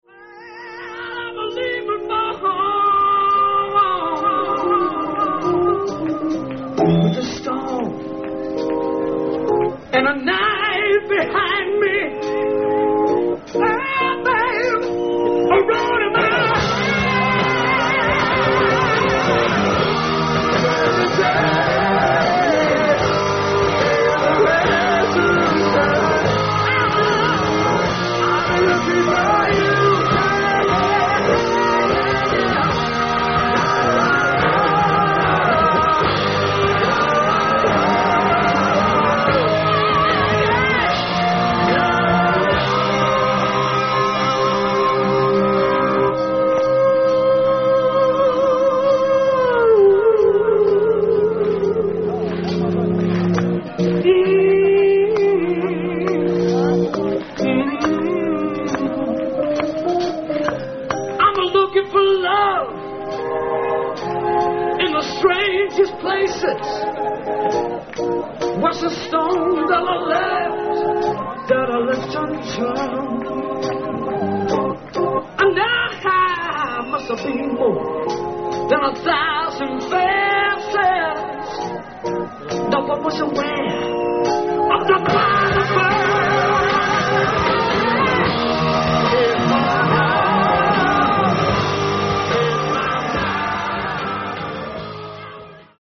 Venue:  Ruisrock Festival
Sound:  Remastered
Source:  Audience